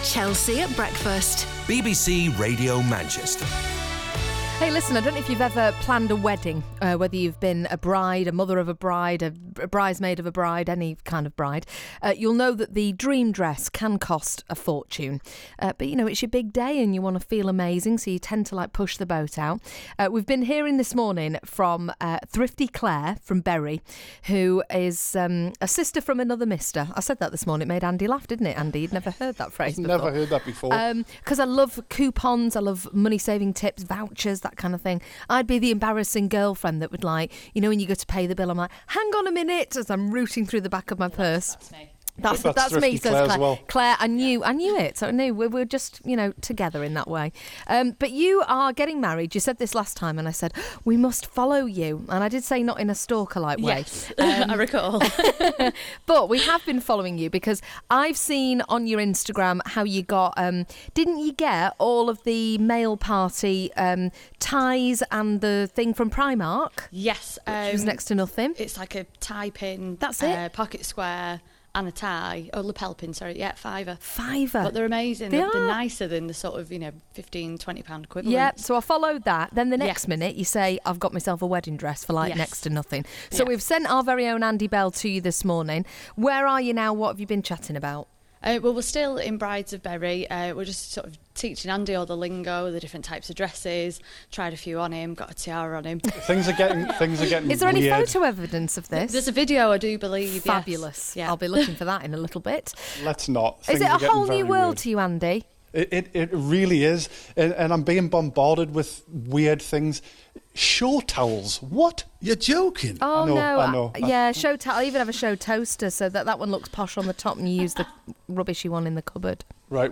Interview part 2: